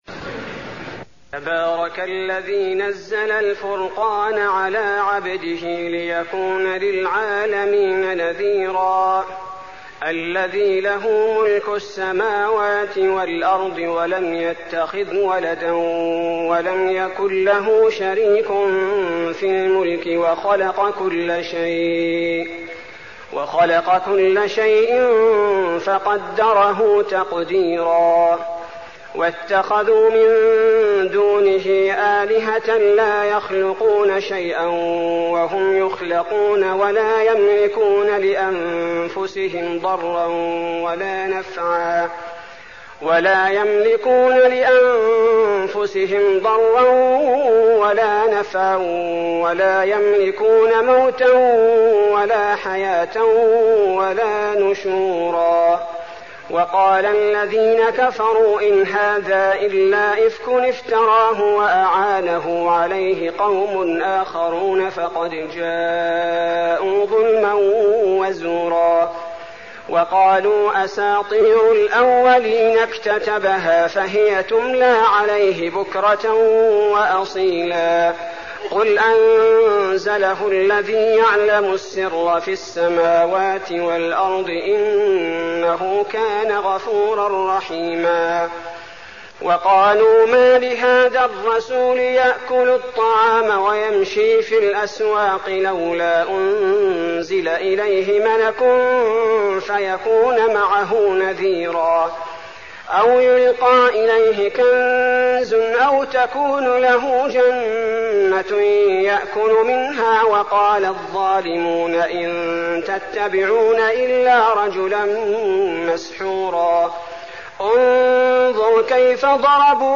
المكان: المسجد النبوي الفرقان